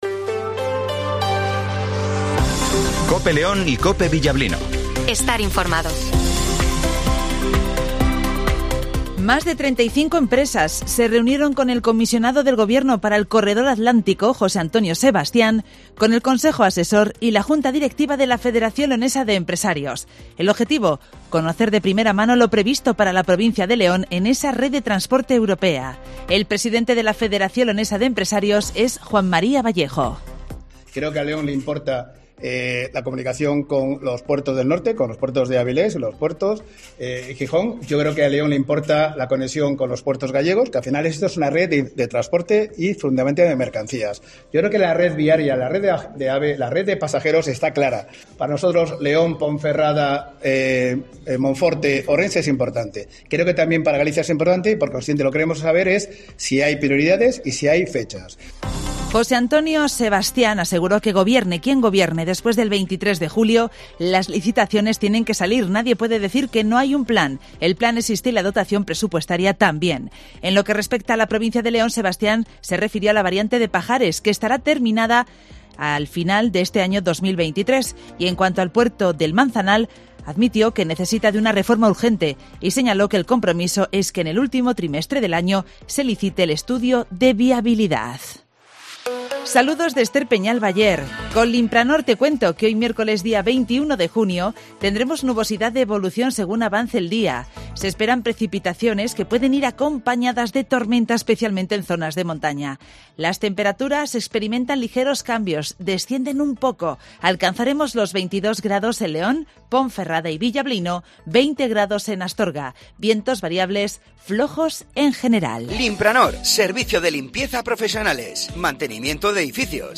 Redacción digital Madrid - Publicado el 21 jun 2023, 08:25 - Actualizado 21 jun 2023, 13:56 1 min lectura Descargar Facebook Twitter Whatsapp Telegram Enviar por email Copiar enlace - Informativo Matinal 08:25 h